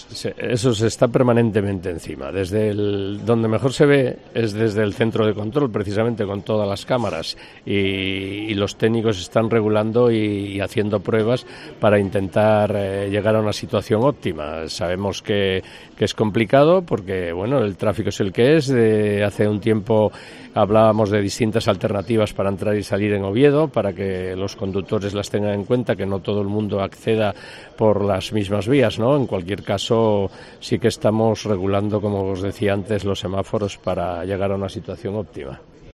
José Ramón Prado explica las mejoras para reducir atascos en la glorieta de la Cruz Roja